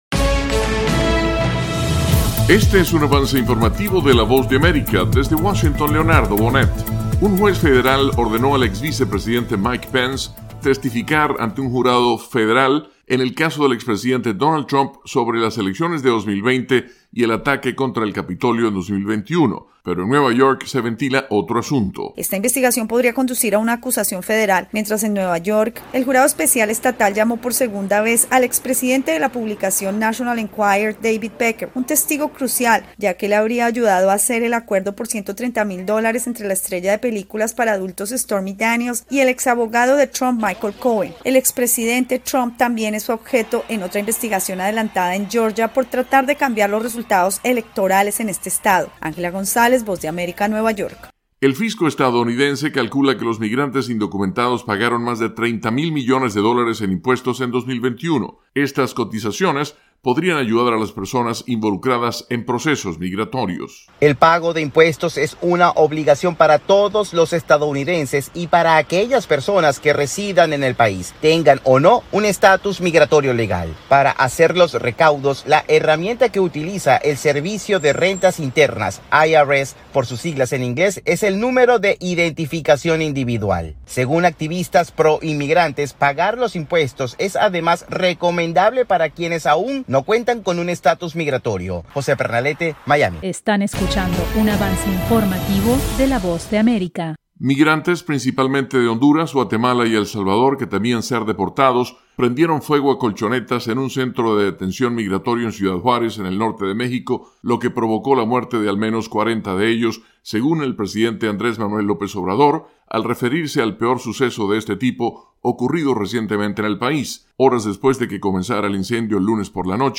El siguiente es un avance informativo presentado por la Voz de América, desde Washington